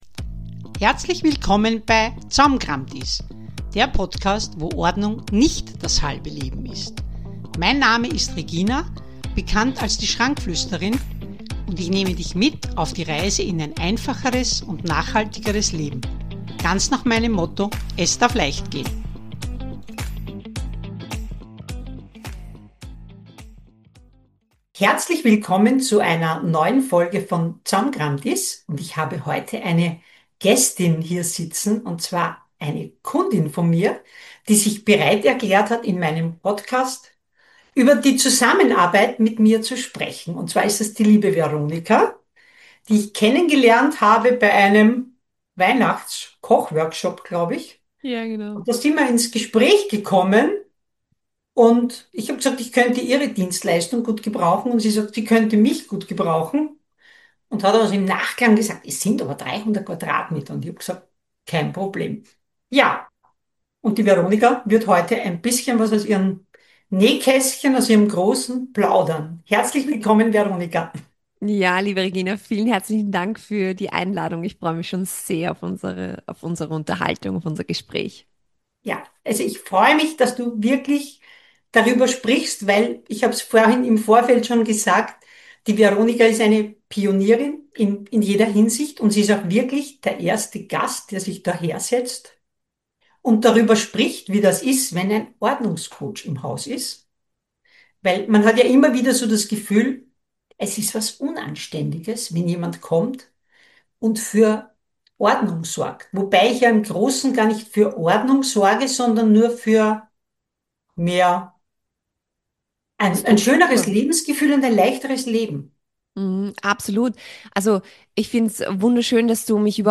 Eine ganze Familie, die gemeinsam anpackt – vom Papa bis zu den kleinsten Familienmitgliedern. In dieser Folge habe ich meine Kundin zu Gast, die erzählt, wie wir ihr Zuhause in nur acht Wochen komplett ausgemistet haben – und wie sich ihr Familienleben dadurch verändert hat.